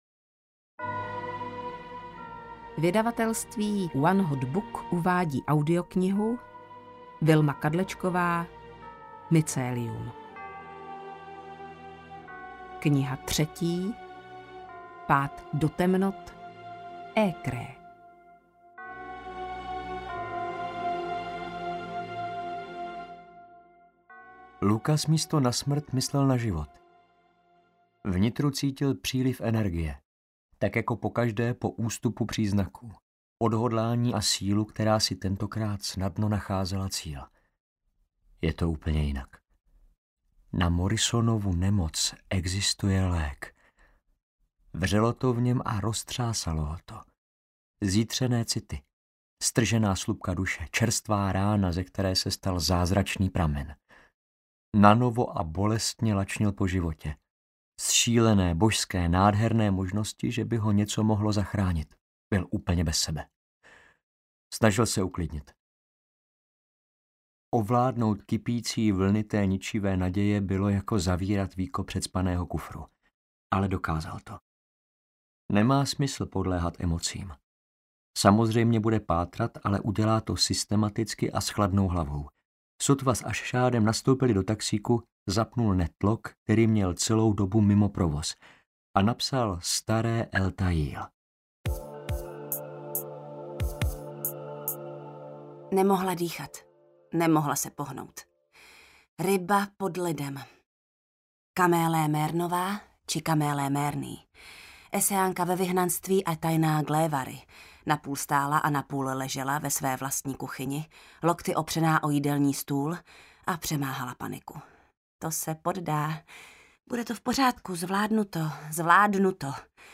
Audio knihaMycelium III: Pád do temnot
Ukázka z knihy